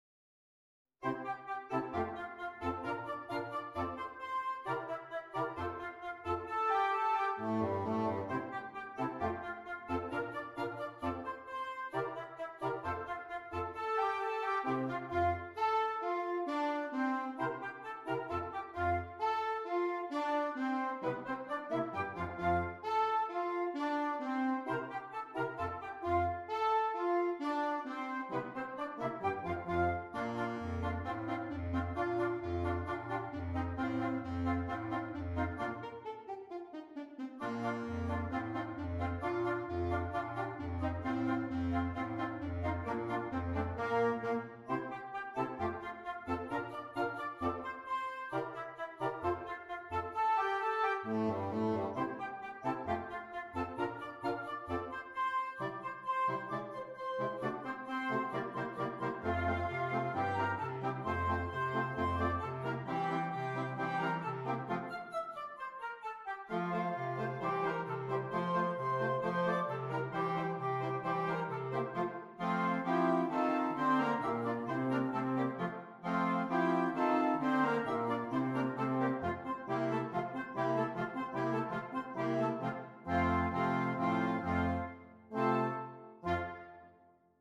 Interchangeable Woodwind Ensemble